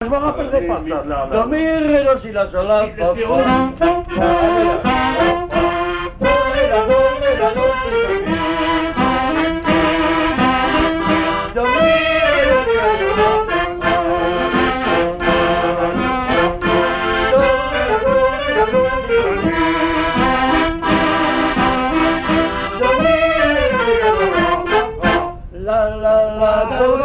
danses des années 1950
Pièce musicale inédite